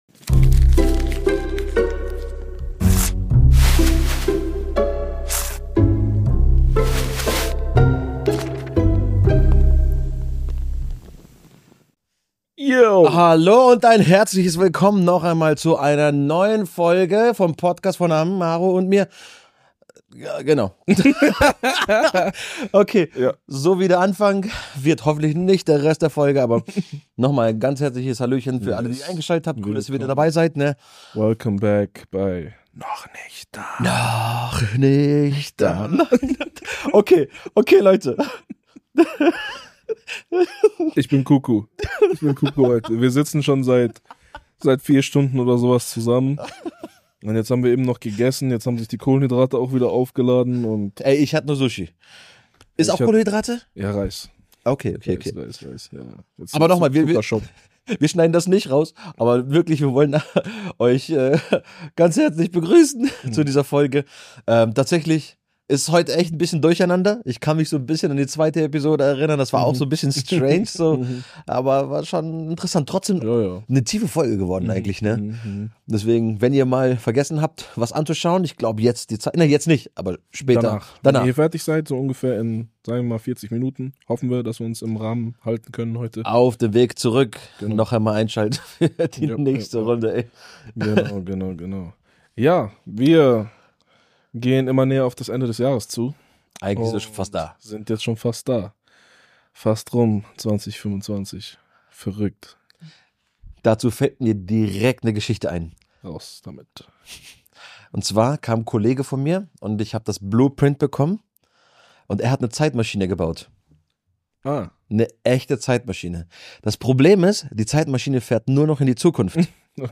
Ein entspannter Talk mit Rückblick, Ausblick und einer Prise Chaos.